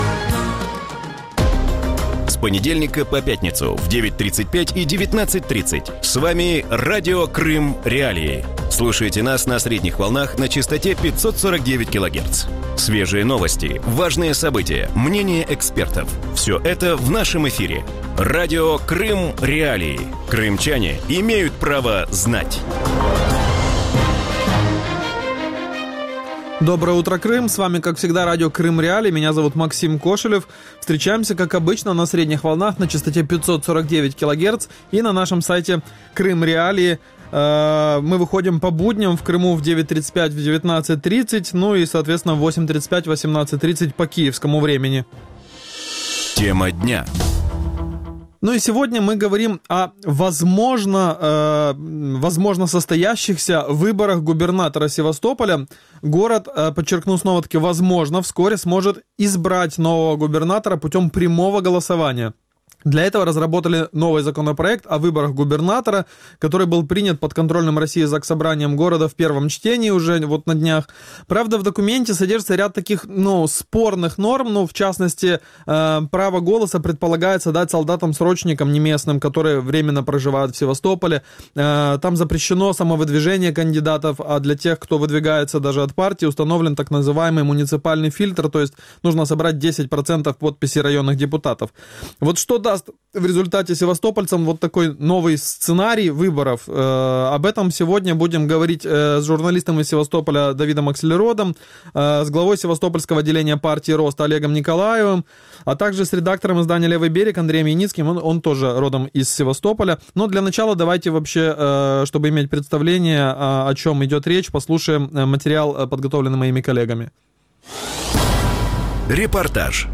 В утреннем эфире Радио Крым.Реалии говорят о выборах губернатора Севастополя. Город федерального значения вскоре сможет избрать нового губернатора путем прямого голосования.